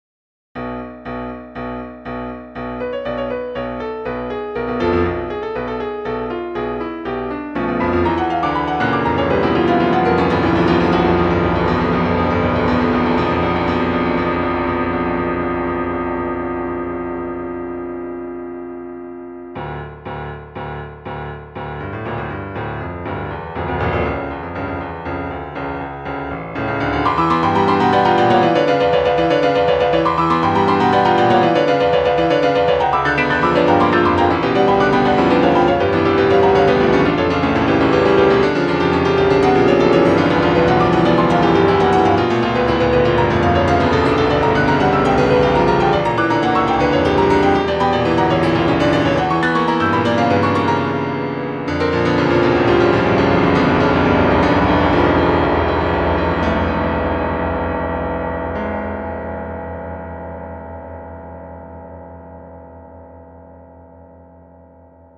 Op.8 (Everything on fire) - Piano Music, Solo Keyboard - Young Composers Music Forum